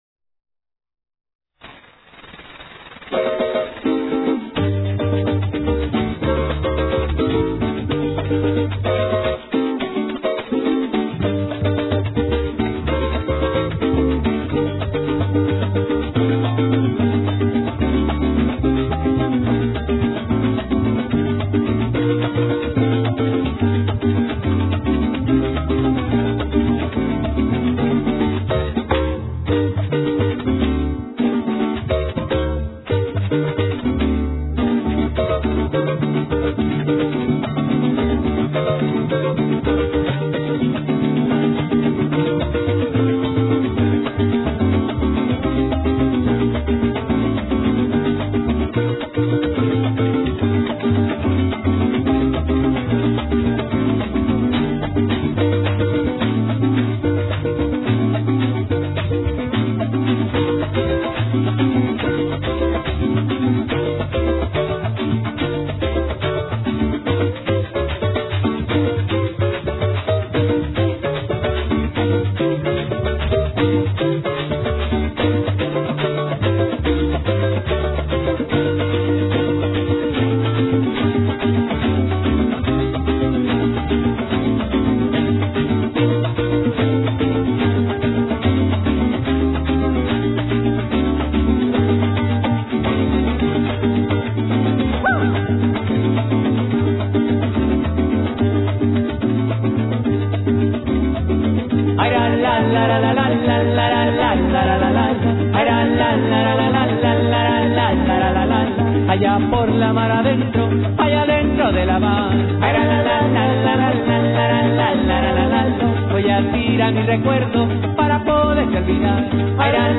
Lo-Fi  mp3  format-